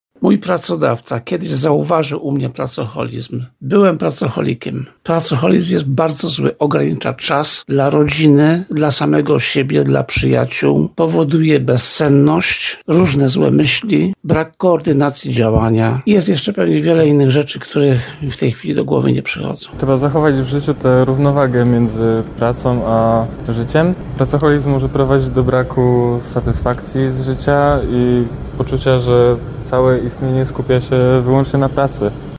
Zapytaliśmy mieszkańców regionu czy są uzależnieni od pracy.